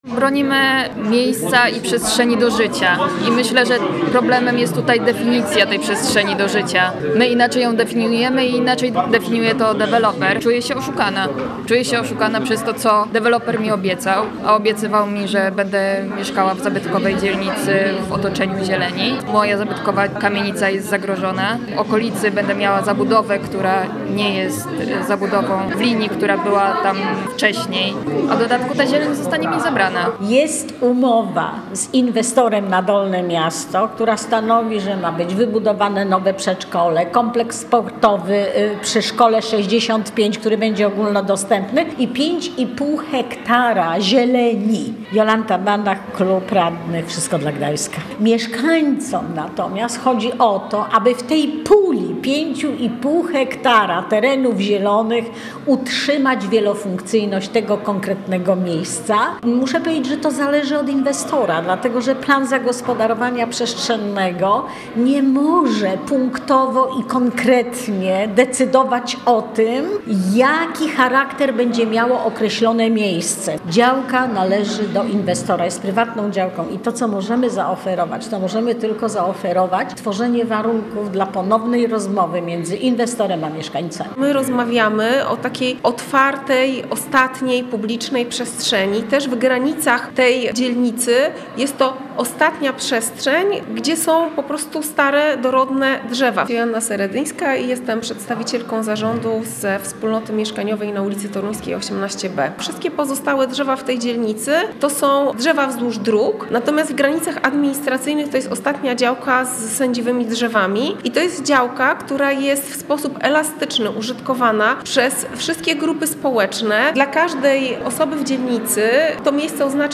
Posłuchaj materiału reportera: